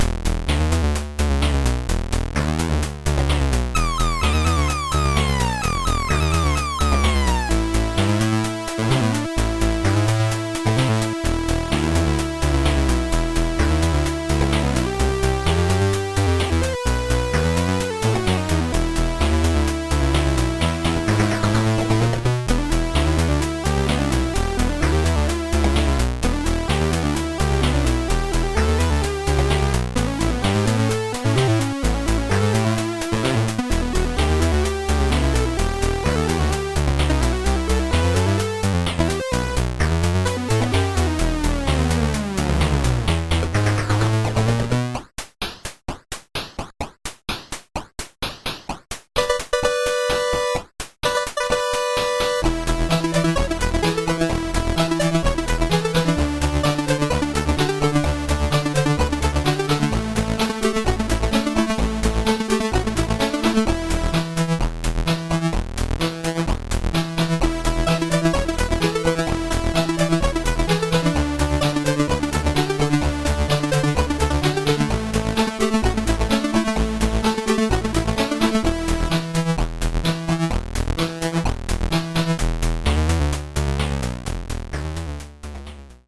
Commodore 64 rendition